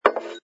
sfx_put_down_glass06.wav